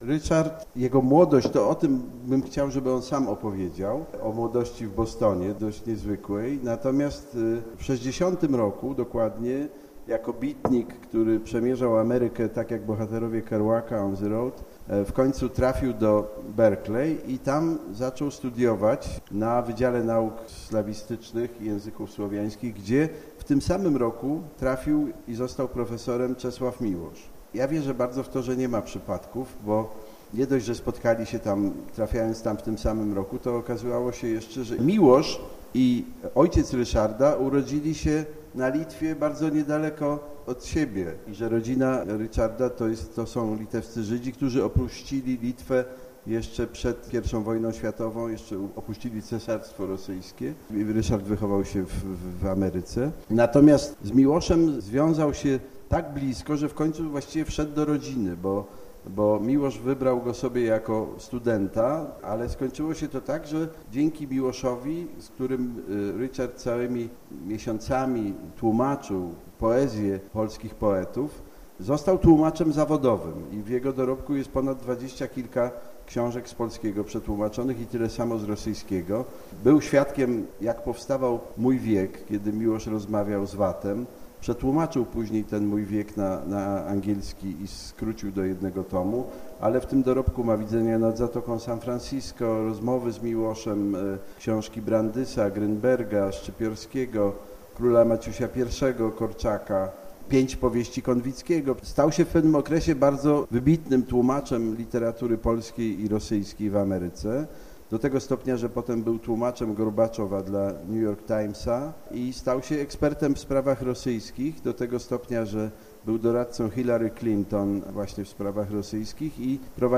Richard Lourie - reportaż